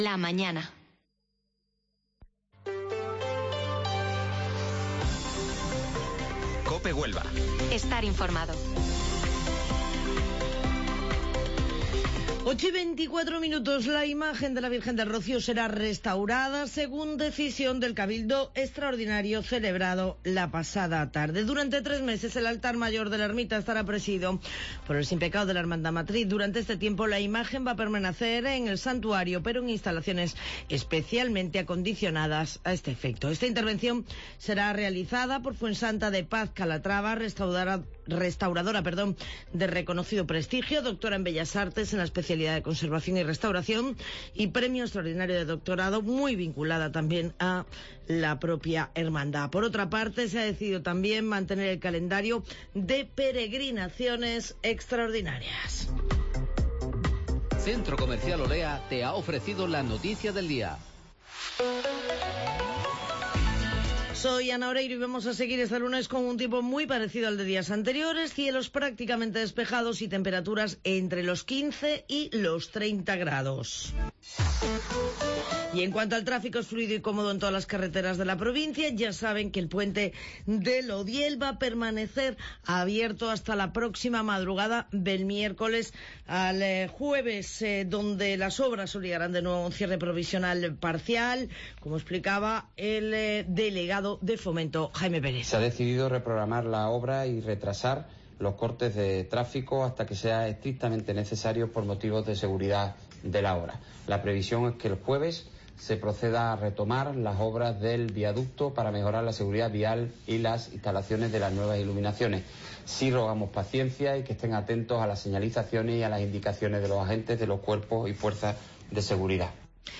Informativo Matinal Herrera en COPE 25 de septiembre